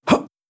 Player_Jump 03.wav